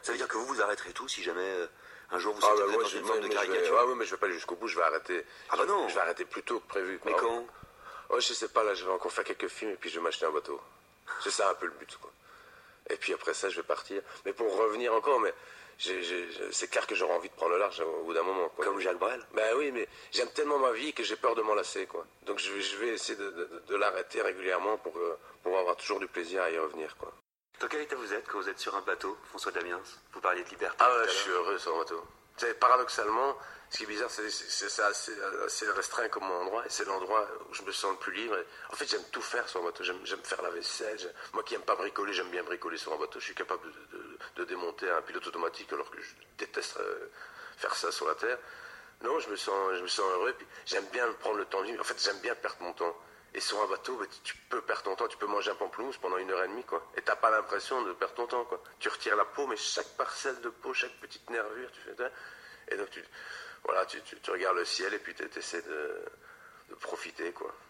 François Damiens était à la radio il y a quelque temps, à l’occasion de la sortie de son film, Mon Ket (que je n’ai pas très envie de voir).
Alors voici un aspect de sa personnalité découvert dans cette interview très vivante.